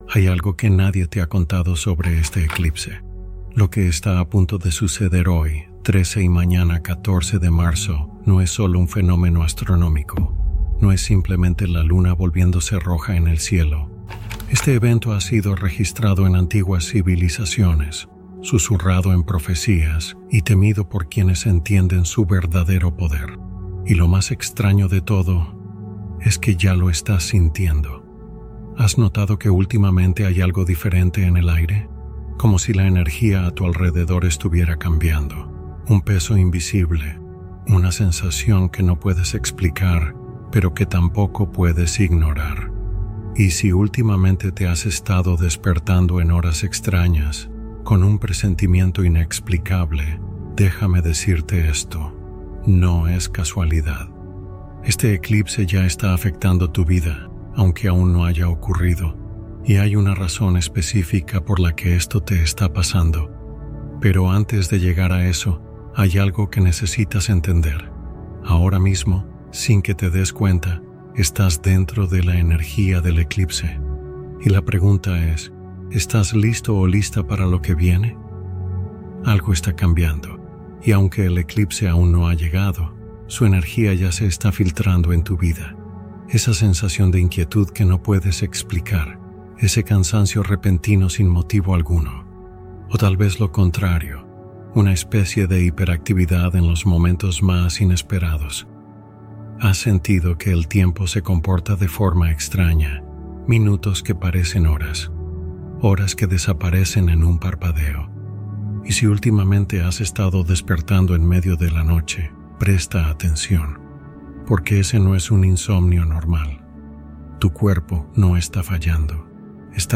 Prepárate Para Esta Noche: Meditación Durante el Eclipse